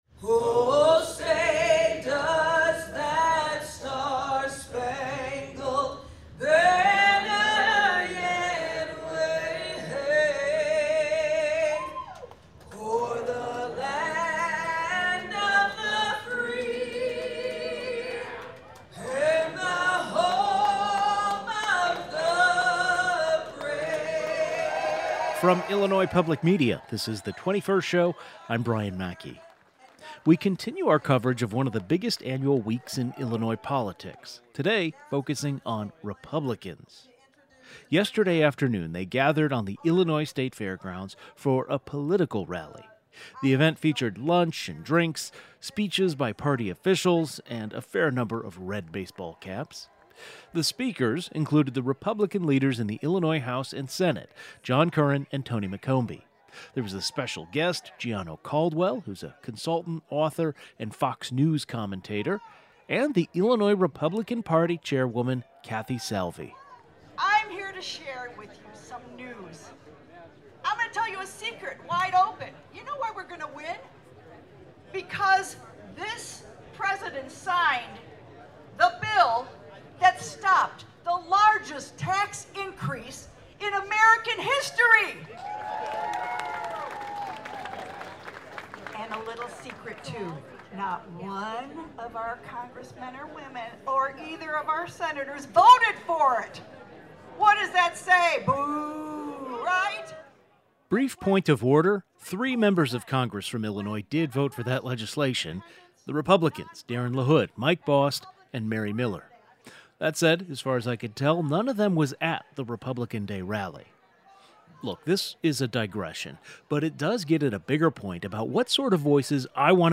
We take listeners to Republican Day at the Illinois State Fair. Plus, an interview with Illinois Department of Agriculture Director Jerry Costello.